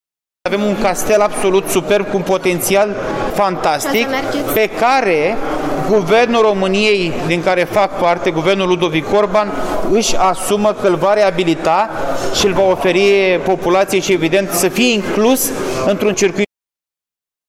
Ministrul Mediului, Costel Alexe:
ALEXE-CASTEL.mp3